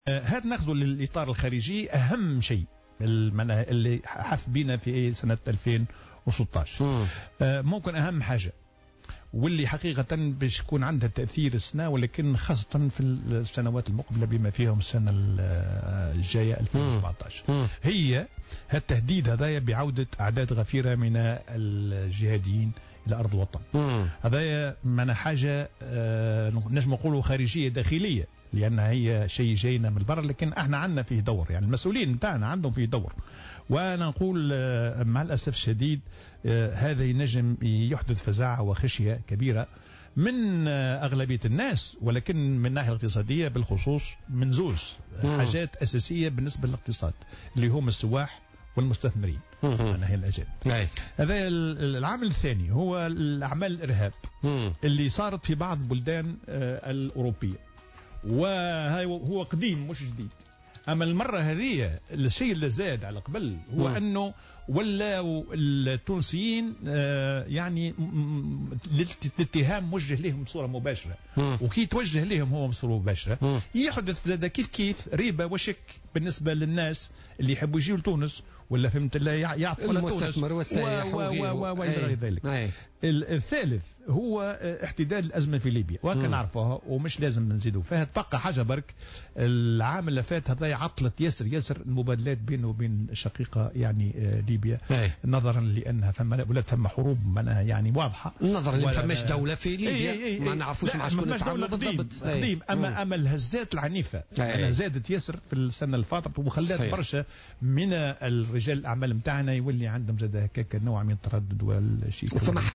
وأوضح الديماسي، ضيف برنامج "بوليتيكا" أن عودتهم ستخلق حالة من الفزع لدى السياح والمستثمرين وستؤثر سلبا على نسق الاستثمار وعلى القطاع السياحي في تونس.